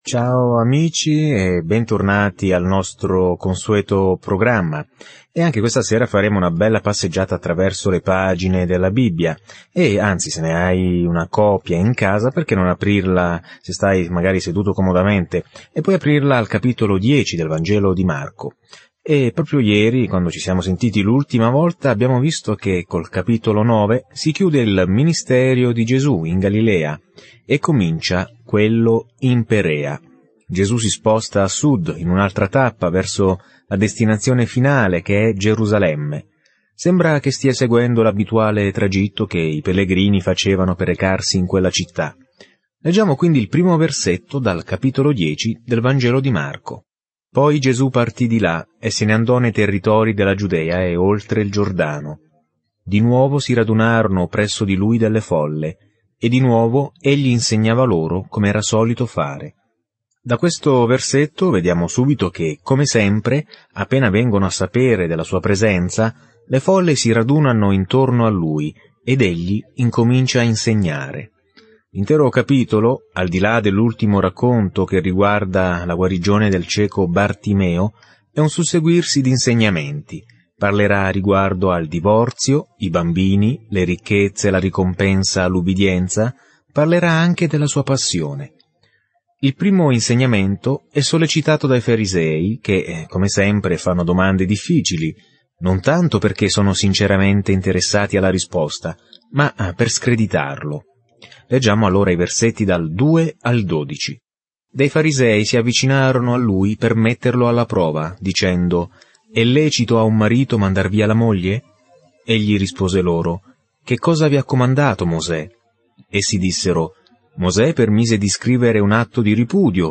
Scrittura Vangelo secondo Marco 10:1-27 Giorno 13 Inizia questo Piano Giorno 15 Riguardo questo Piano Il Vangelo più breve di Marco descrive il ministero terreno di Gesù Cristo come Servo sofferente e Figlio dell’uomo. Viaggia ogni giorno attraverso Marco mentre ascolti lo studio audio e leggi versetti selezionati della parola di Dio.